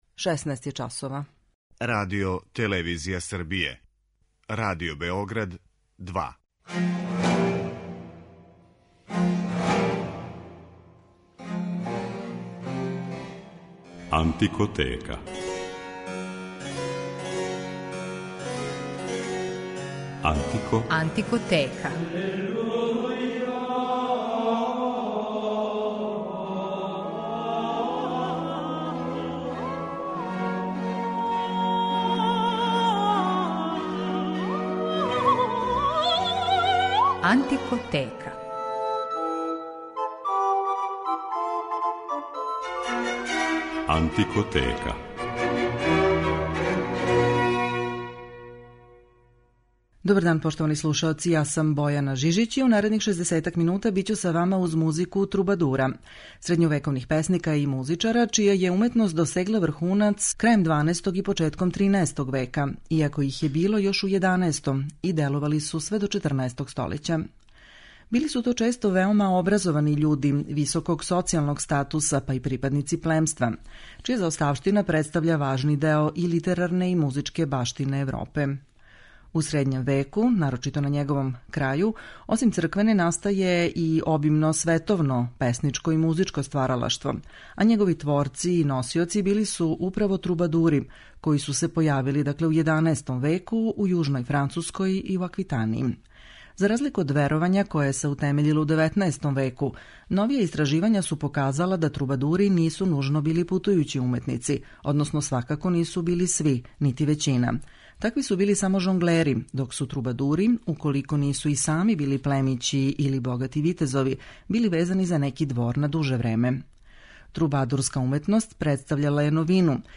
Управо из овог периода су и шансоне које ћете моћи да слушате.